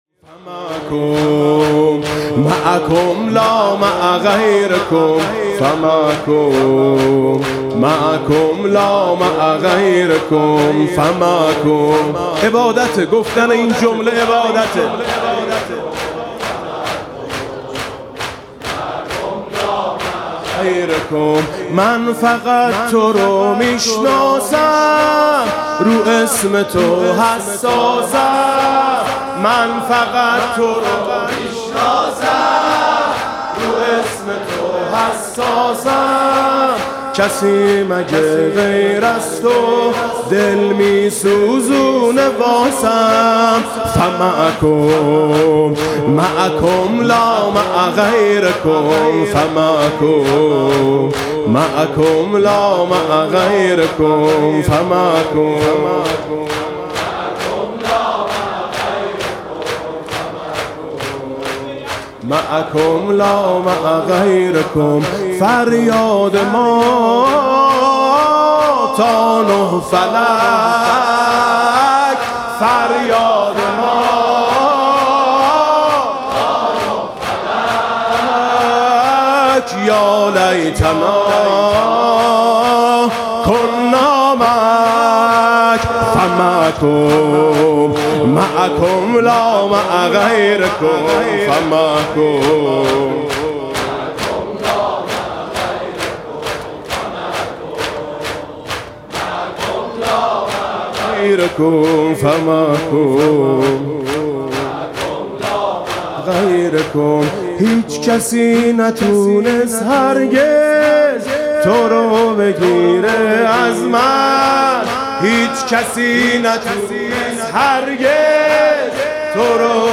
(شور)